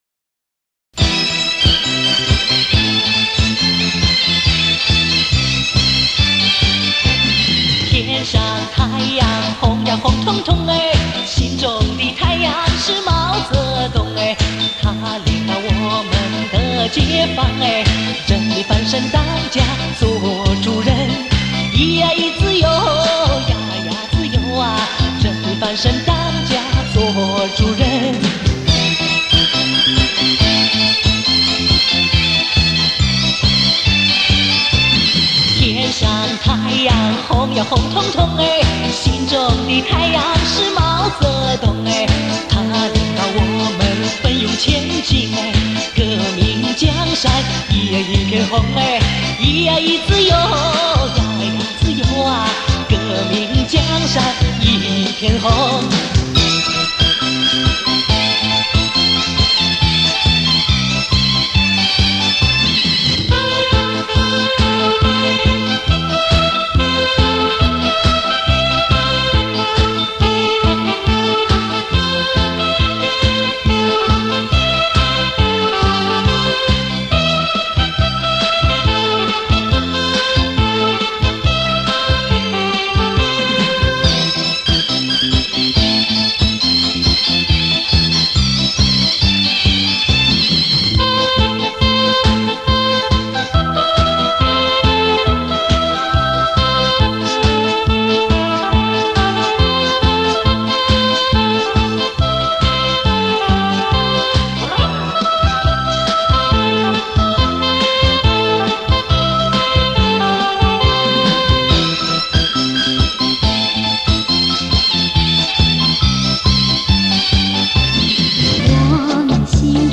mao zedong propaganda music Red Sun.mp3